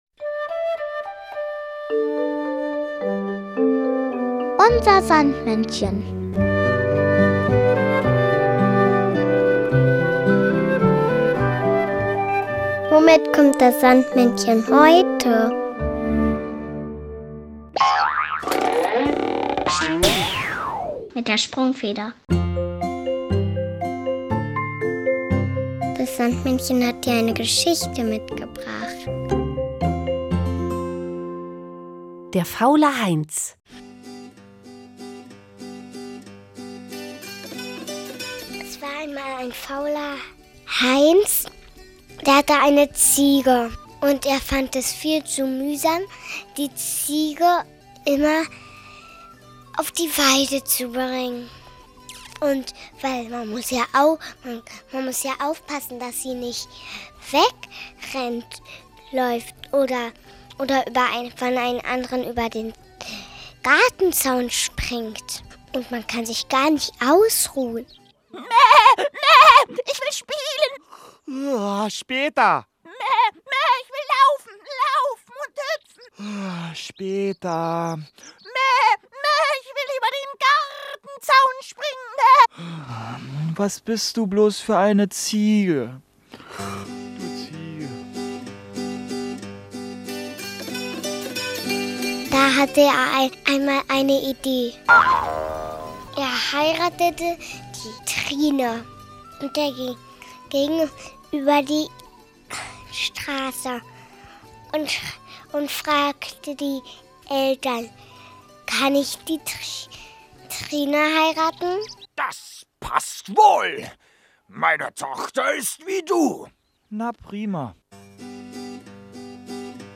Märchen: Der faule Heinz
sondern auch das Kinderlied "Keine Lust" von Ulf und Zwulf.